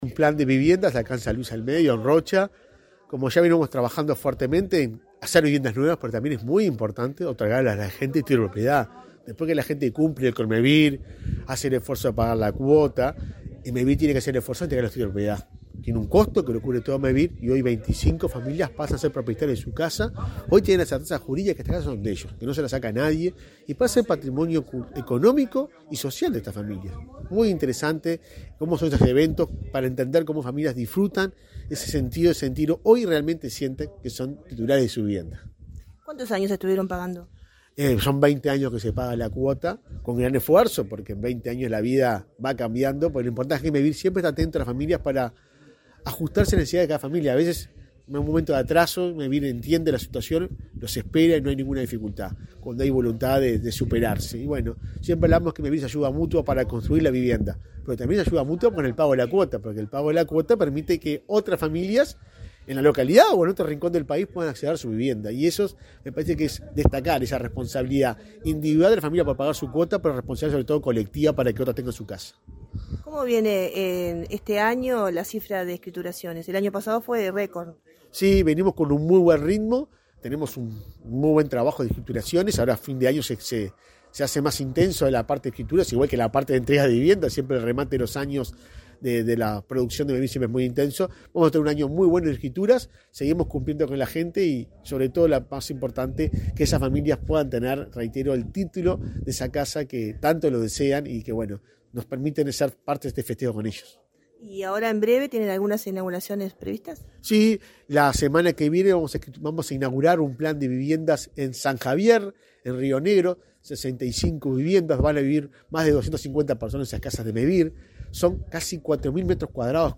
Entrevista al presidente de Mevir, Juan Pablo Delgado
Entrevista al presidente de Mevir, Juan Pablo Delgado 20/10/2022 Compartir Facebook X Copiar enlace WhatsApp LinkedIn El presidente de Mevir, Juan Pablo Delgado, dialogó con Comunicación Presidencial luego de presidir el acto de escritura colectiva de 25 viviendas en la localidad de San Luis al Medio, departamento de Rocha.